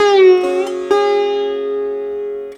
SITAR LINE48.wav